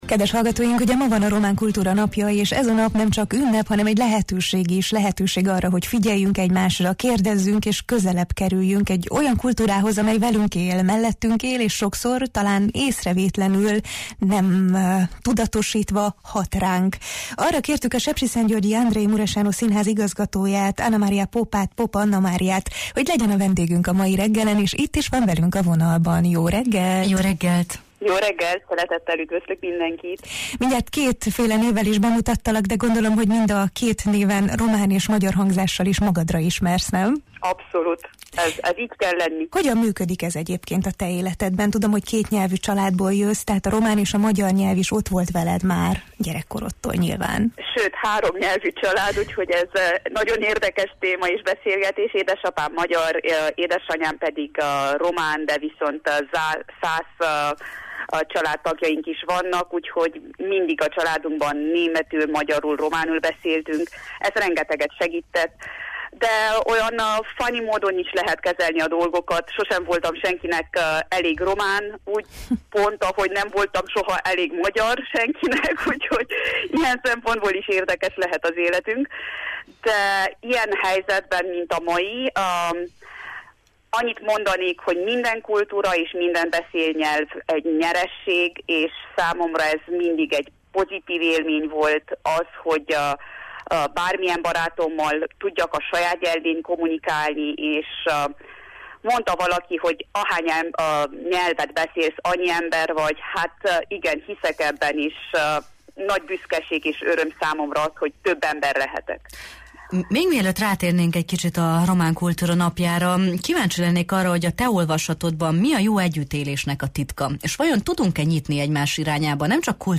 A magyar nyelvű beszélgetés során szó esett arról is, hogyan kapcsolódik egymáshoz a román és a magyar kulturális tér Erdélyben, és milyen hidakat építhet a színház a különböző közösségek között.